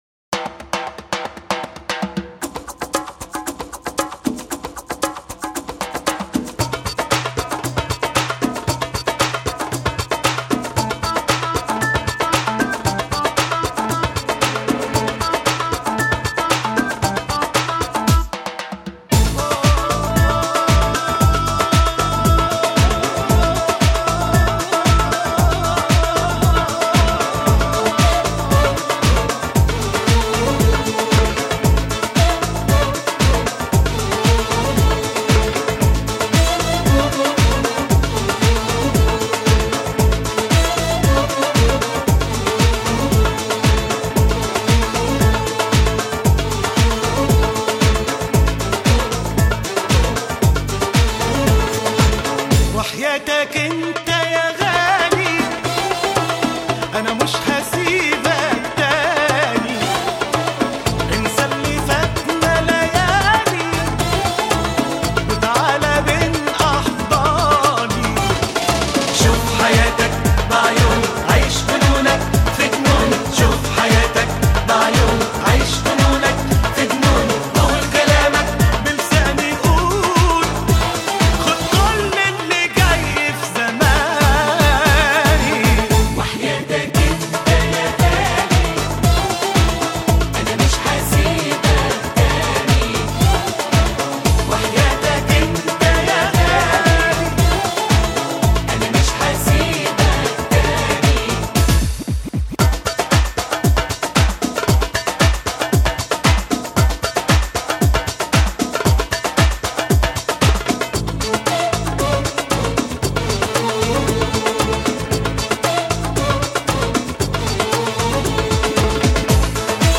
Музыка со словами
Арабская